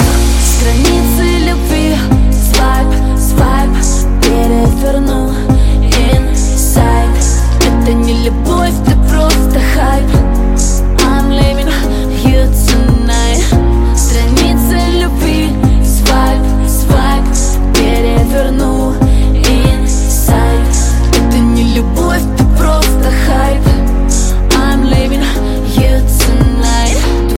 • Качество: 128, Stereo
поп